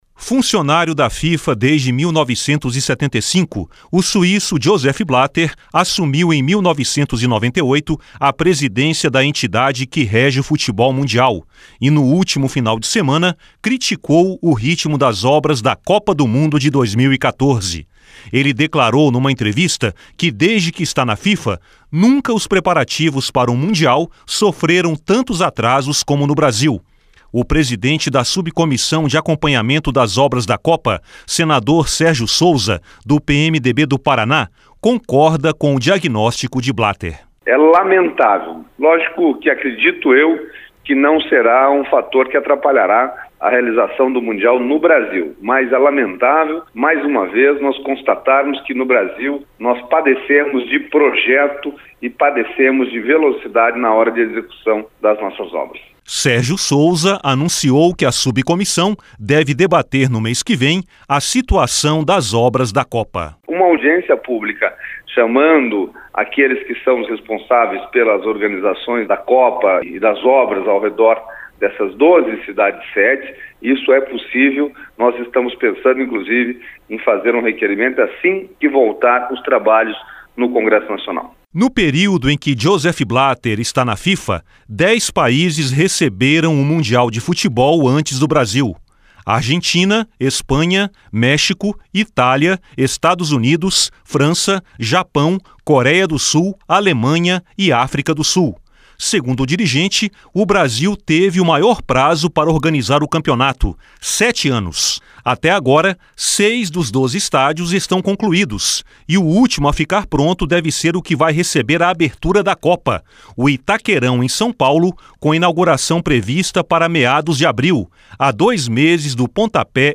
O presidente da Subcomissão de Acompanhamento das Obras da Copa, senador Sérgio Souza, do PMDB do Paraná, concorda com o diagnóstico de Blatter: (S SOUZA) É lamentável.